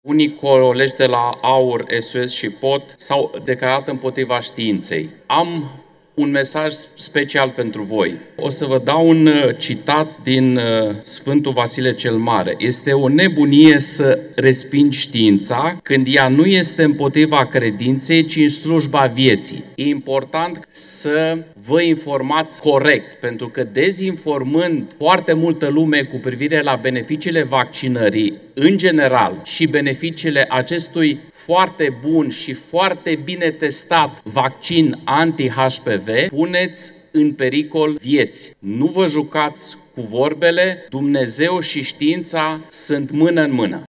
După vot, deputatul USR Emanuel Ungureanu a avut un mesaj pentru parlamentarii partidelor extremiste – AUR, SOS și POT – care au răspândit tot felul de dezinformări despre acest vaccin, dar care acum s-au abținut de la vot ori au lipsit.